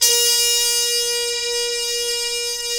Index of /90_sSampleCDs/Roland LCDP06 Brass Sections/BRS_Harmon Sect/BRS_Harmon Tps S